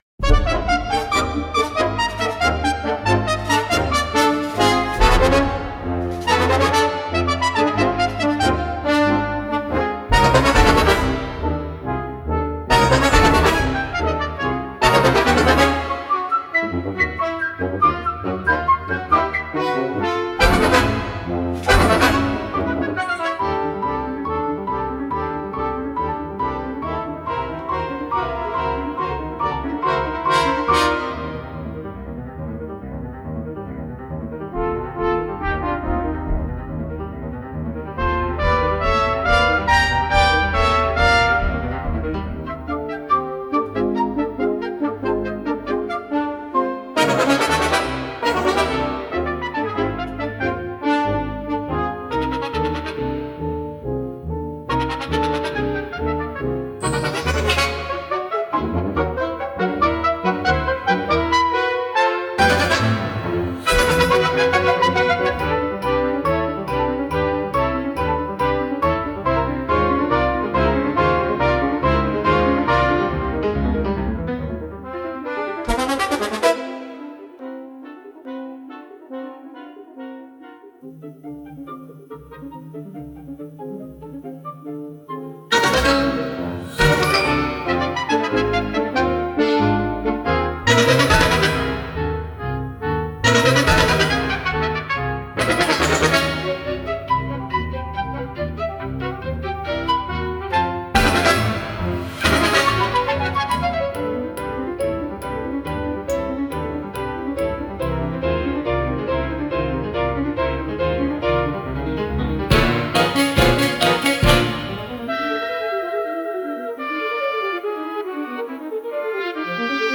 The 12 Day War for Wind Ensemble
I came up with my own system of modulating serialism: Home | Music Set Modulator The modulations work very well, giving the atonal music a sense of perspective and direction. The twelve day war opens with a trumpet fanfare, i...